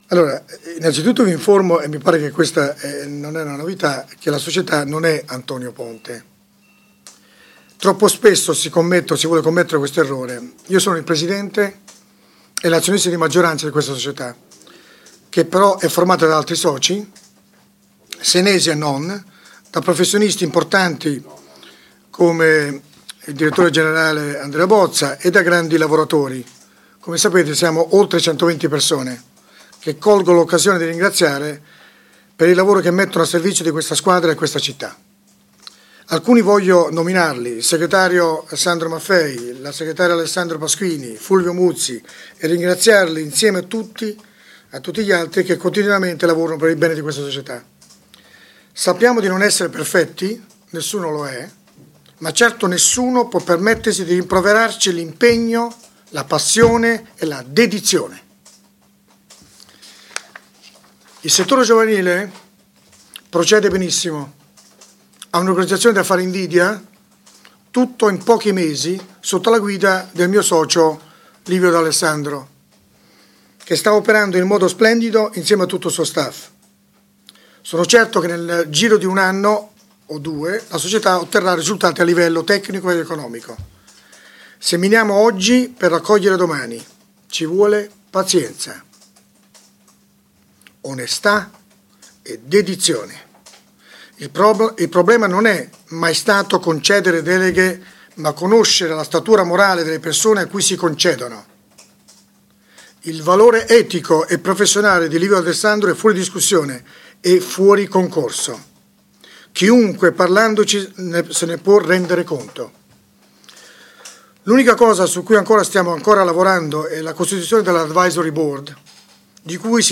Di seguito gli audio della conferenza stampa